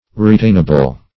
Retainable \Re*tain"a*ble\, a. Capable of being retained.
retainable.mp3